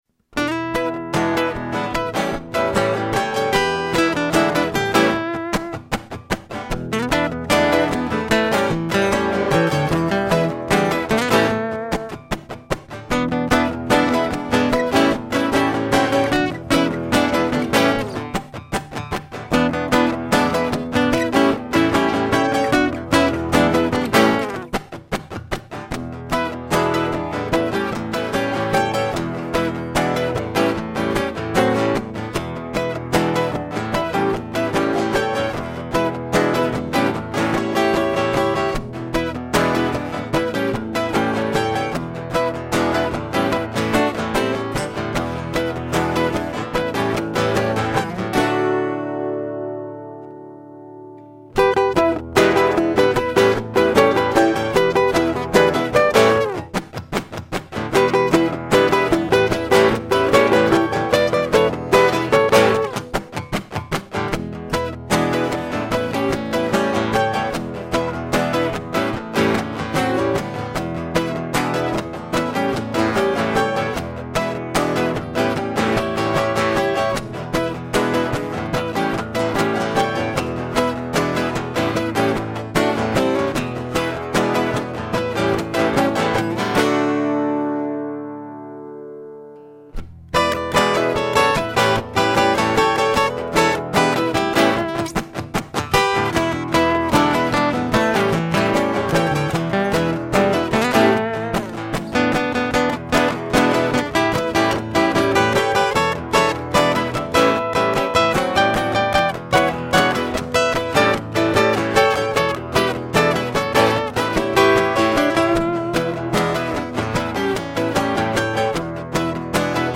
All Original Indy Rock Sound